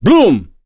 Nicx-Vox_Skill2-2.wav